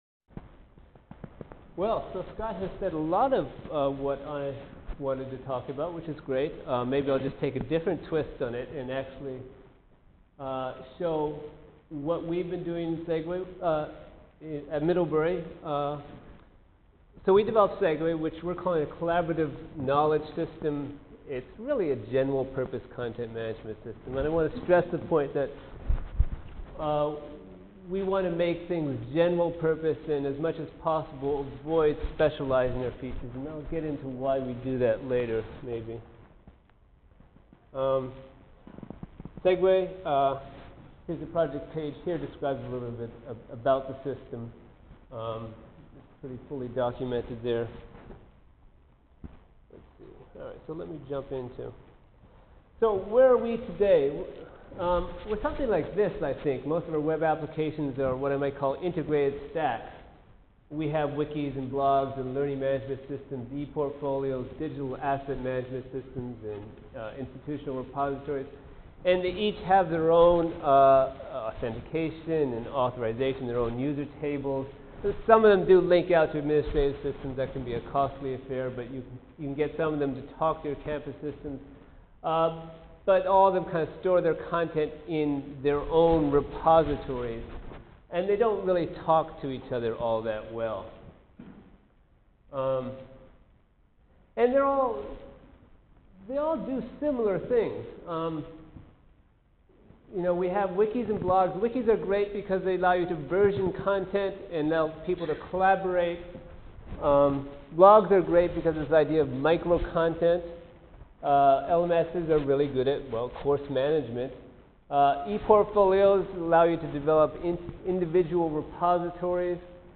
Middlebury College NITLE Symposium: Learning Management Systems at Liberal Arts Colleges October 20-22, 2006.